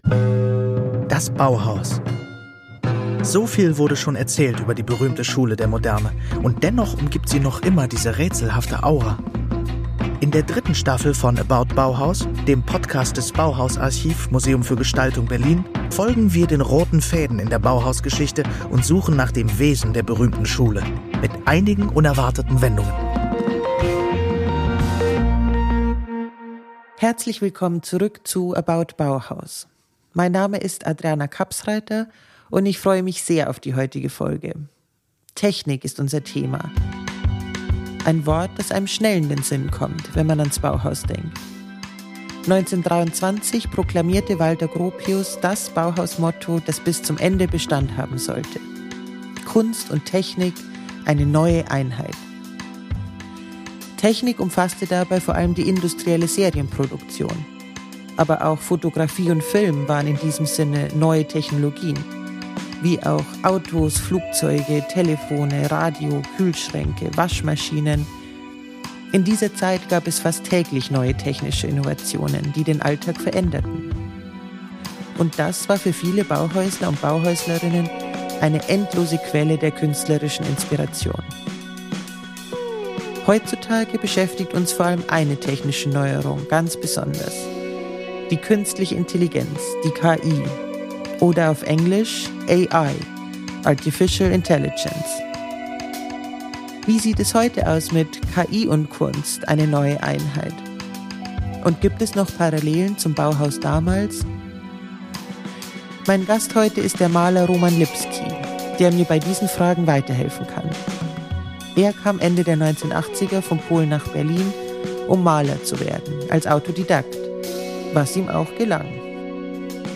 In seinem Atelier in Berlin-Kreuzberg gehen die beiden der Frage nach, was passiert, wenn Maschinen künstlerisch mitgestalten und welche Rolle der gestaltende Mensch dabei noch spielt.